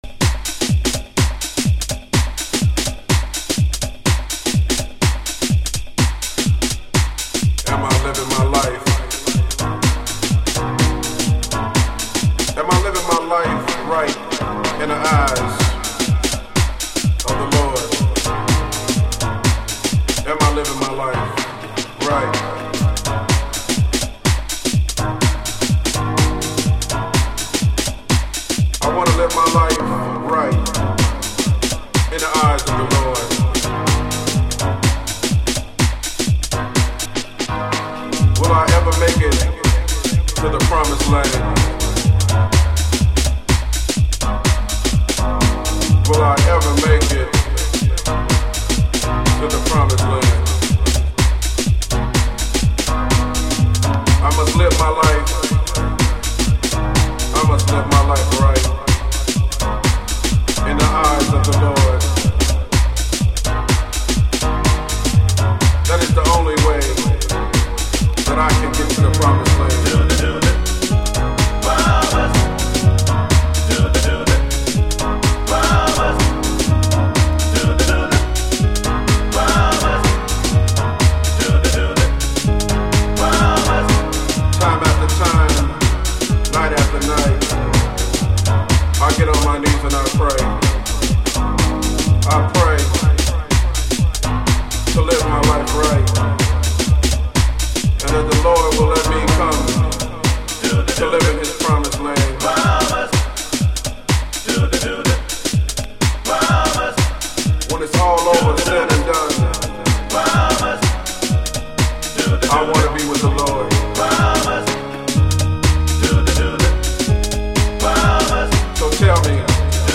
Early House / 90's Techno
サイン波ベースの効いたシカゴディープハウス。